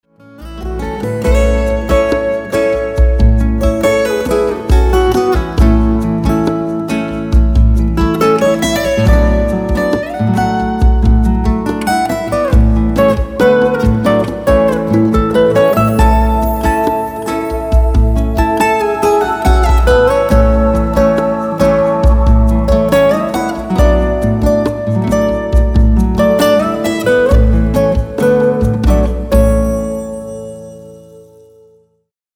guitars, percussion
keyboard